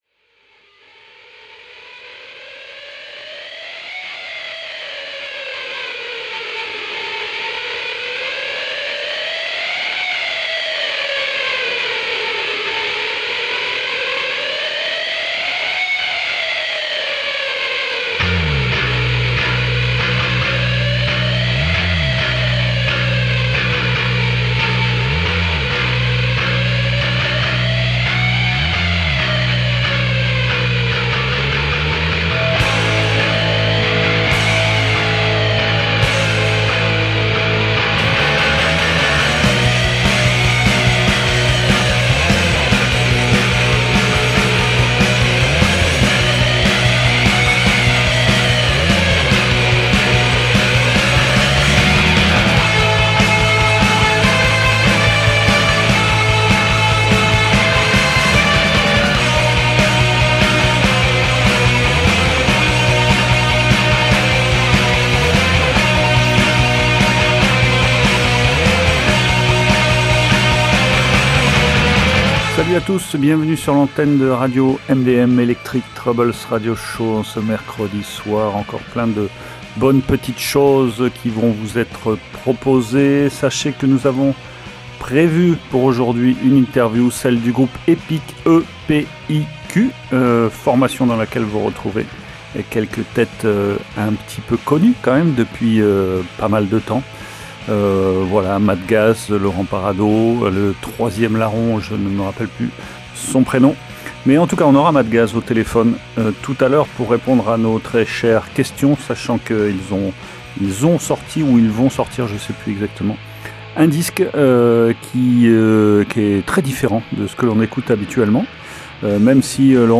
Ce trio instrumental afro world punk
balafon